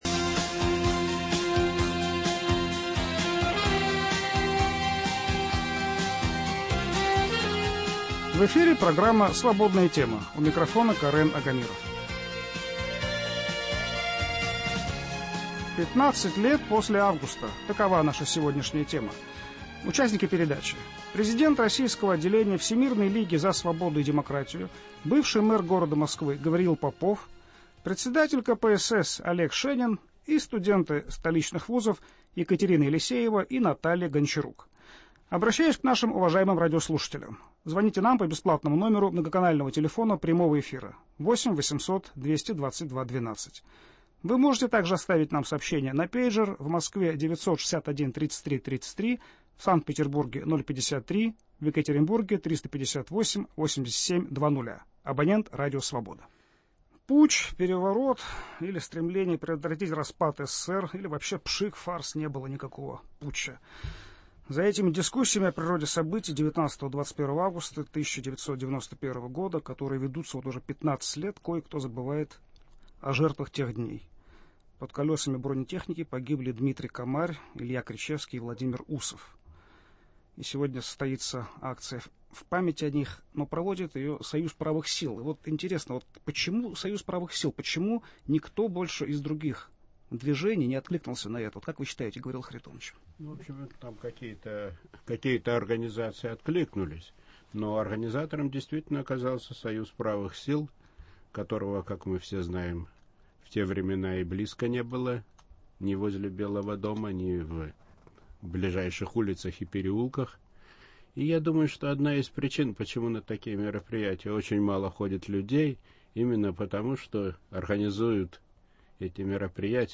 Участники передачи: президент российского отделения Всемирной лиги за свободу и демократию, бывший мэр города Москвы Гавриил Попов, председатель КПСС Олег Шенин и студенты столичных вузов.